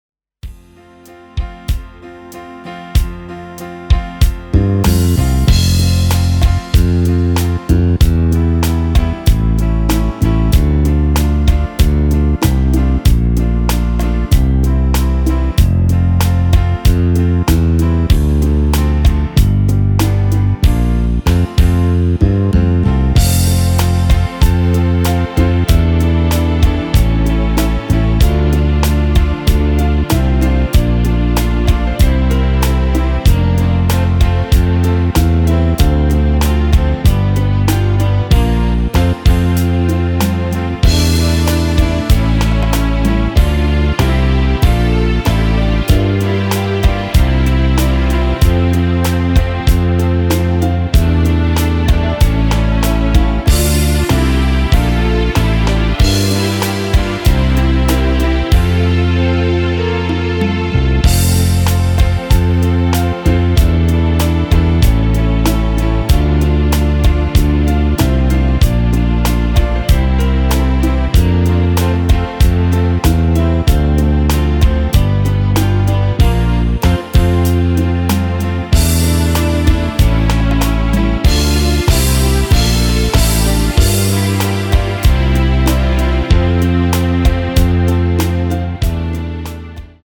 Klavier / Streicher